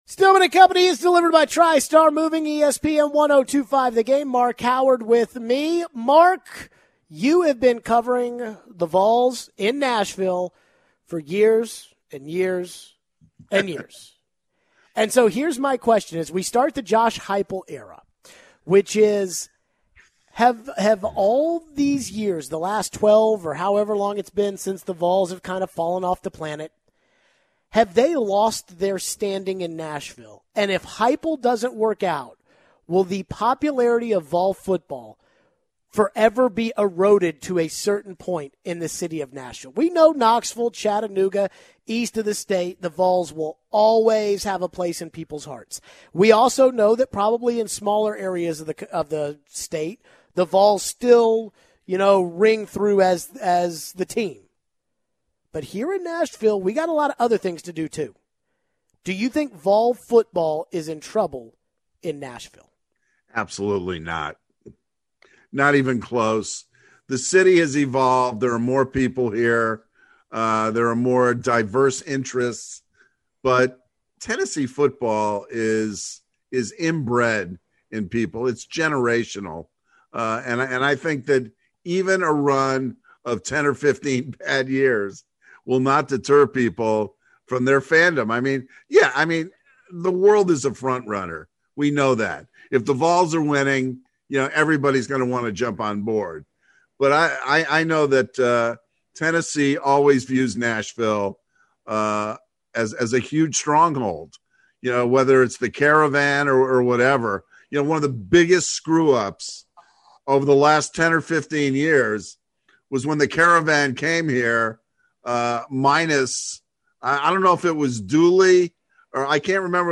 More talk on the Vols hiring of Josh Heupel. What about trying to recruit the state of Tennessee? We take some calls on Vandy hoops and SEC fandom in Nashville.